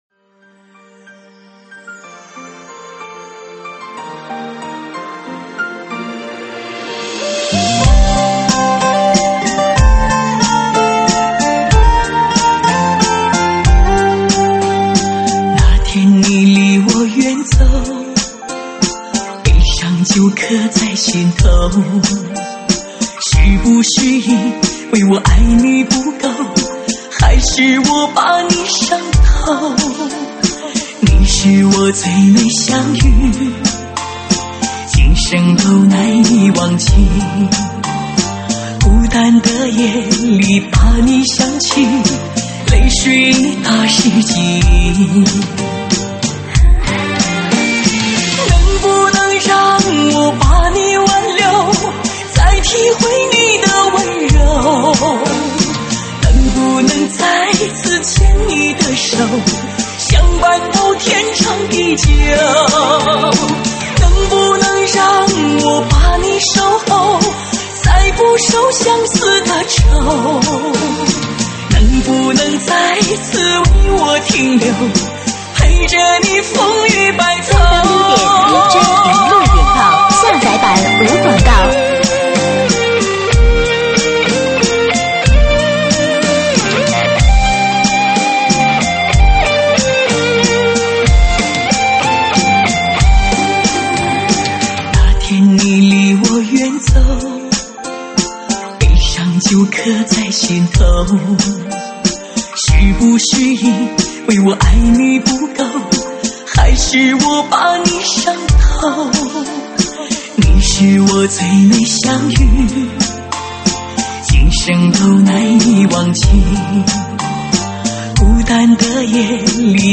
收录于(慢三)